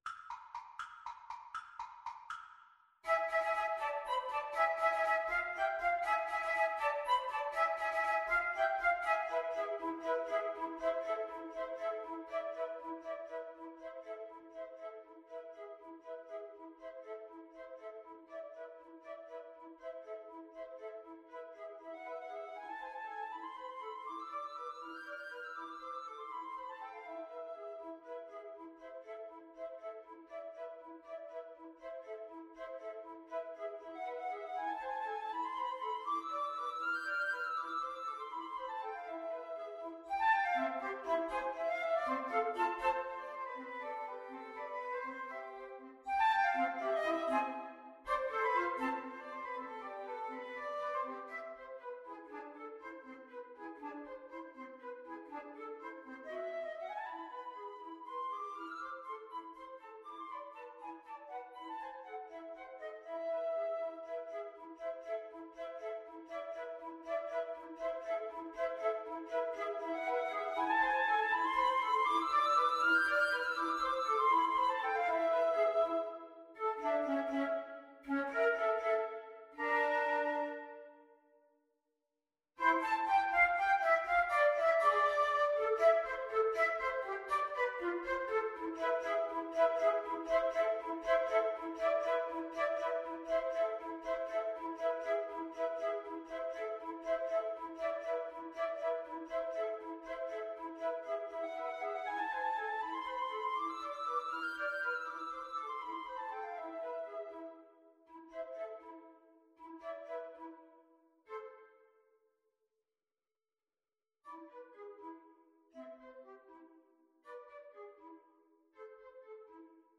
Allegro vivo (.=80) (View more music marked Allegro)